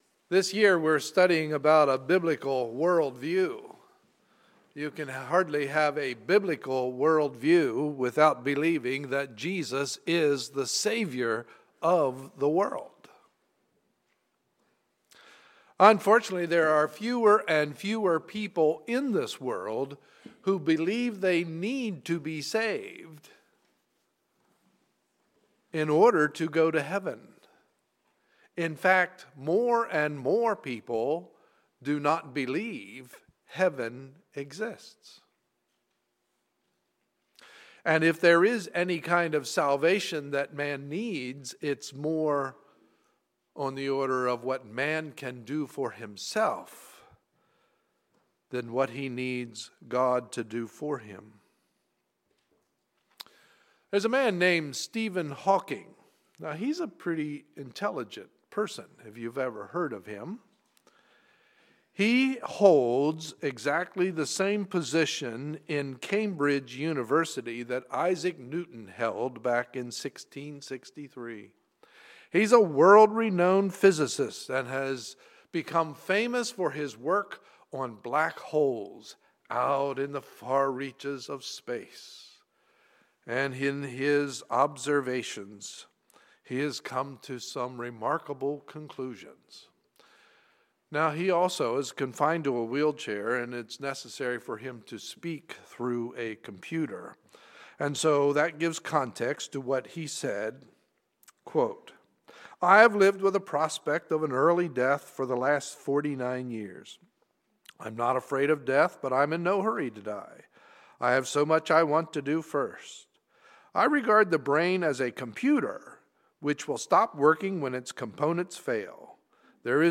Sunday, April 20, 2014 – Morning Service